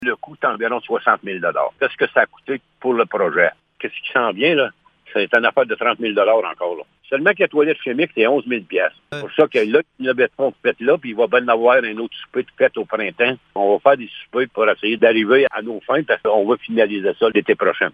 Le maire de Blue Sea, Laurent Fortin, apporte des précisions sur les coûts des travaux :